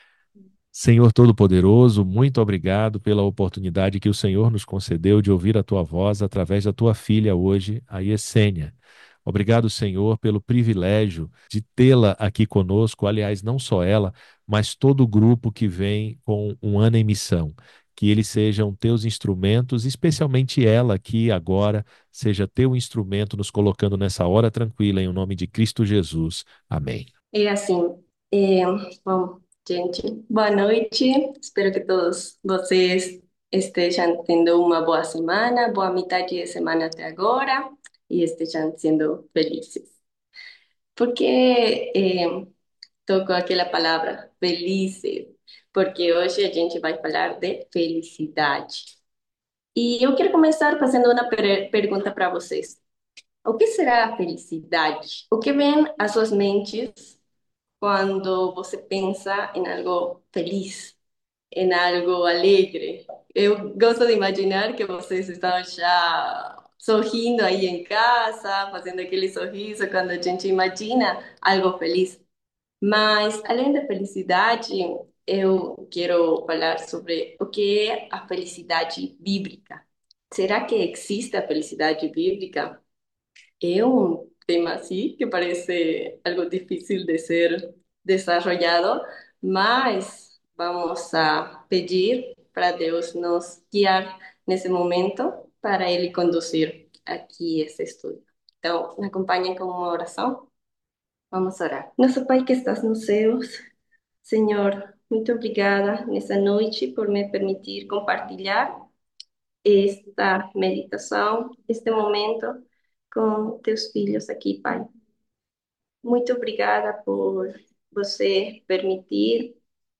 A Hora Tranquila é um devocional semanal.